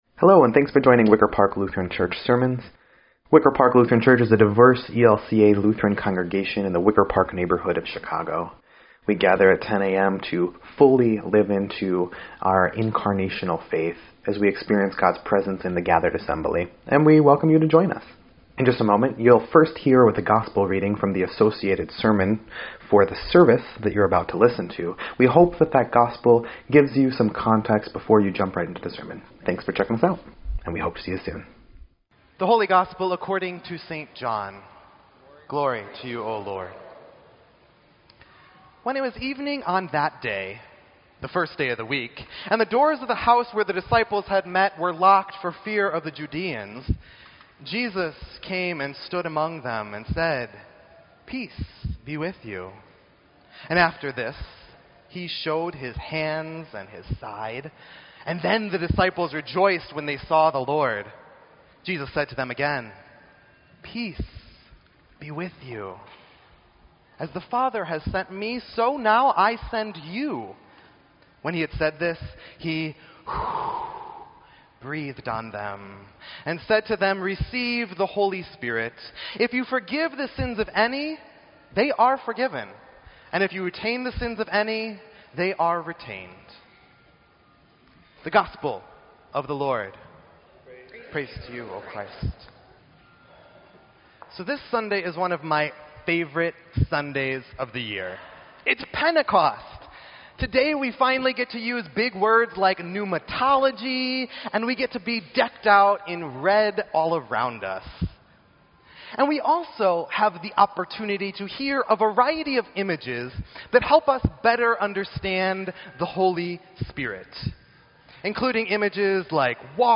Sermon_6_4_17_EDIT.mp3